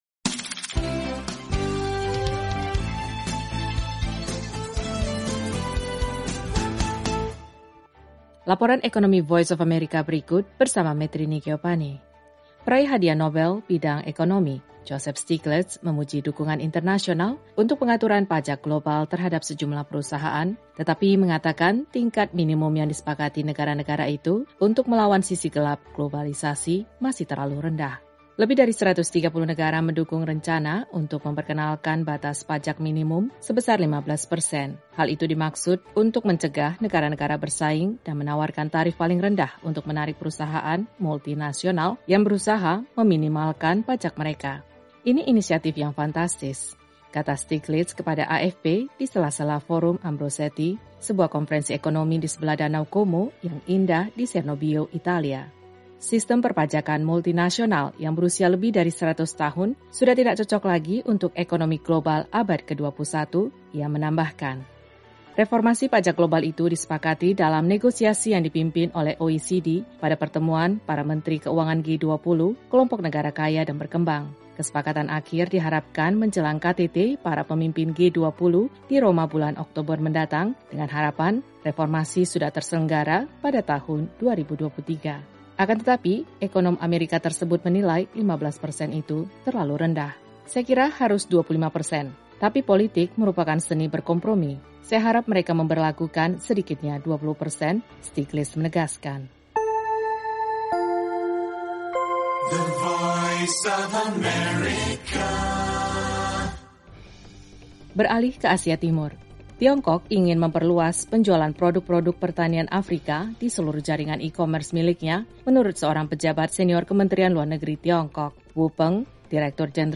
Laporan Ekonomi VOA kali ini mengenai pandangan peraih nobel Stiglitz terkait rencana pajak global harus bertujuan lebih tinggi. Ikuti juga laporan tentang Tiongkok yang akan mengimpor lebih banyak produk pertanian Afrika.